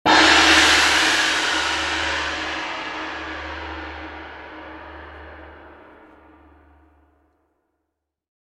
Глубокие вибрации и переливчатые обертоны подойдут для медитации, звукотерапии или создания атмосферы в творческих проектах.
Звучание китайского гонга